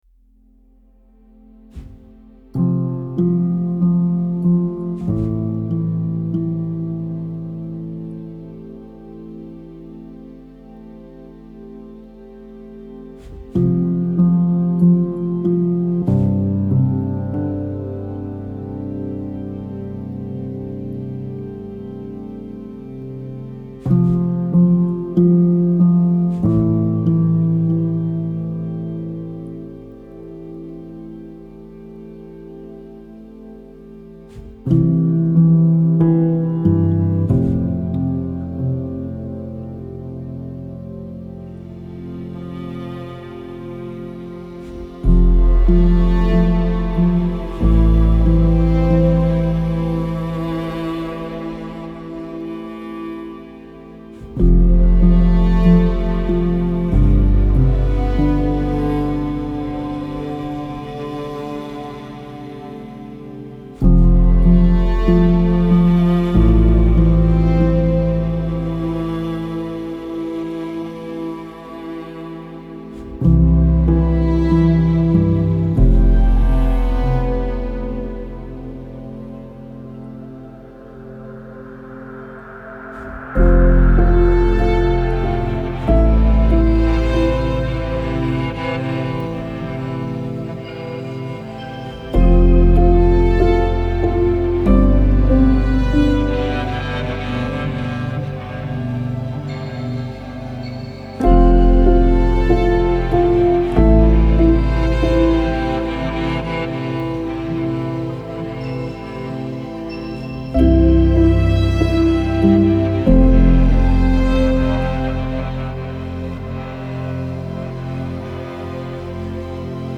عمیق و تامل برانگیز